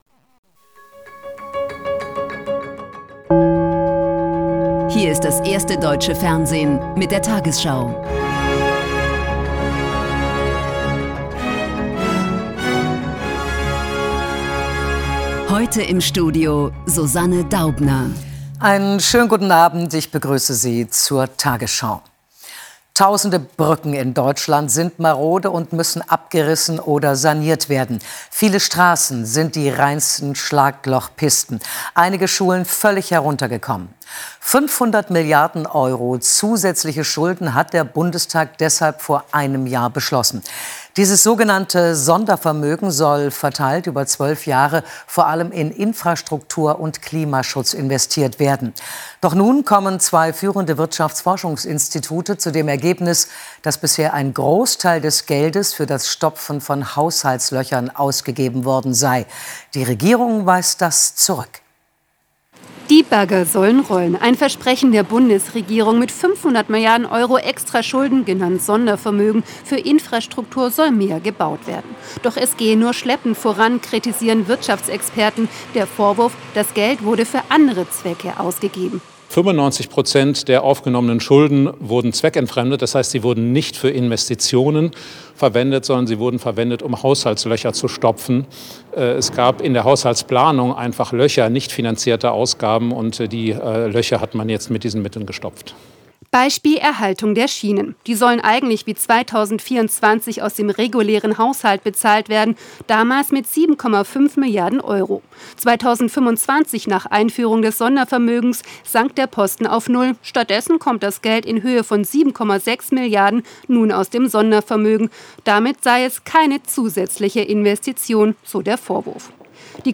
tagesschau 20:00 Uhr, 17.03.2026 ~ tagesschau: Die 20 Uhr Nachrichten (Audio) Podcast